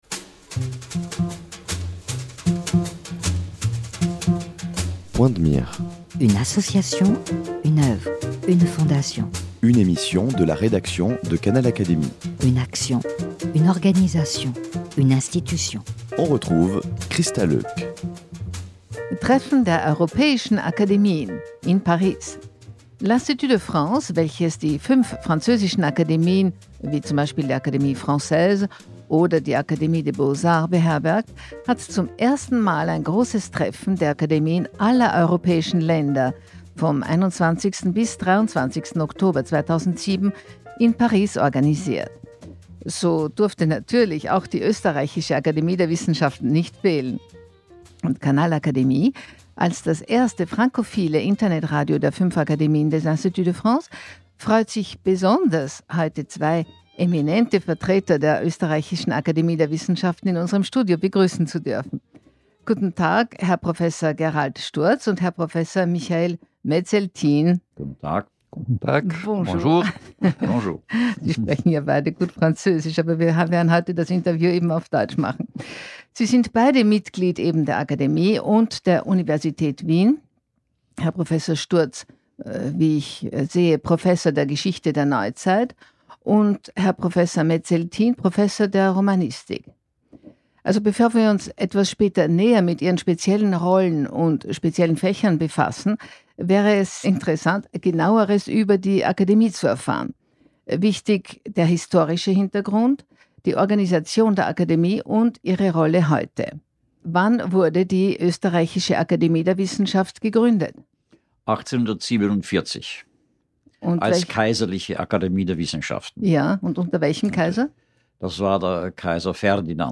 Canal Académie hatte zwei eminente Vertreter der ÖAW in unserem Studio zu Gast